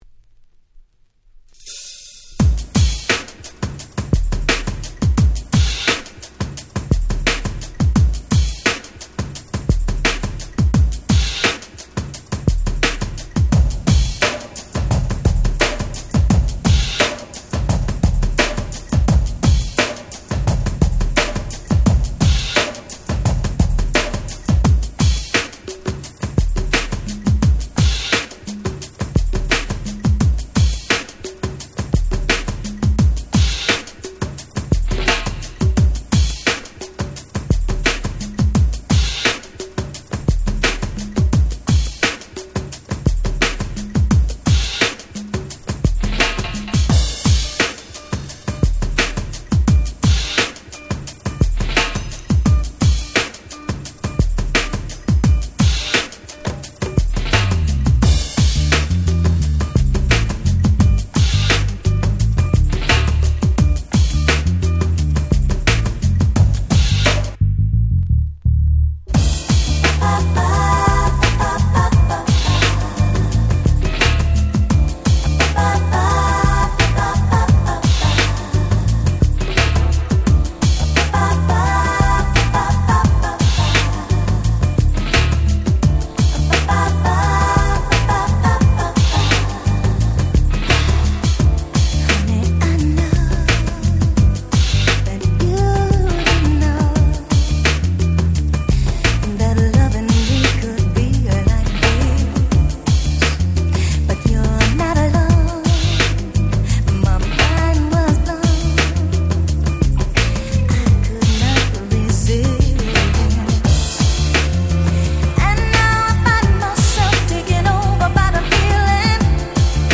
92' Very Nice R&B !!
超セクシーで超最高！！
透明感ハンパない！！！